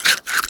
comedy_bite_creature_eating_06.wav